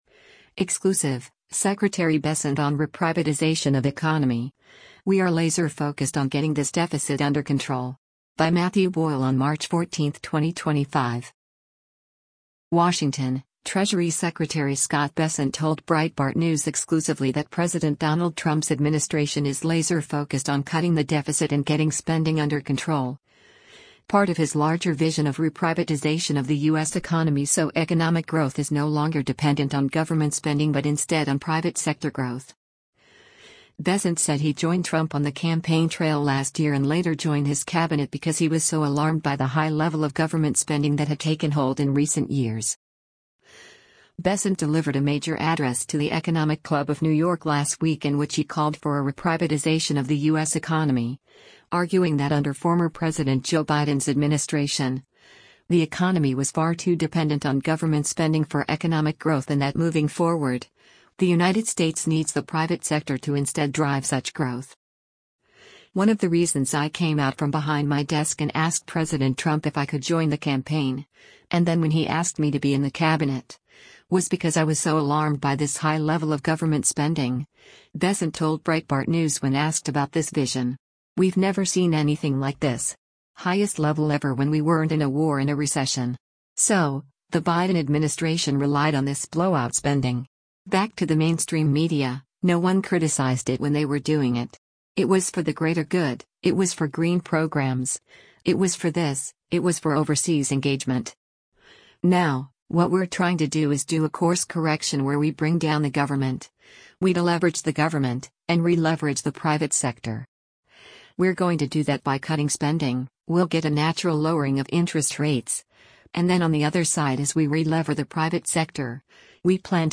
Much more from Bessent’s exclusive interview with Breitbart News at the Treasury Department is forthcoming.